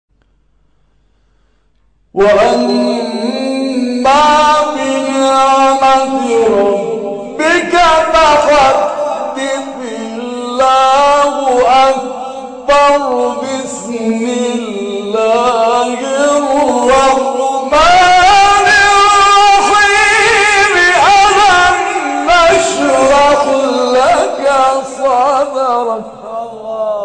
شبکه اجتماعی: فرازهای صوتی از تلاوت قاریان ممتاز و بین المللی کشور که به تازگی در شبکه‌های اجتماعی منتشر شده است، می‌شنوید.